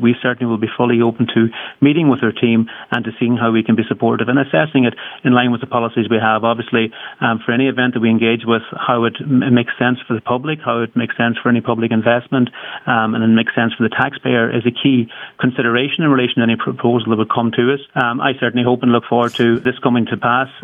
Minister McConalogue expressed his interest in meeting with Taylor’s representatives: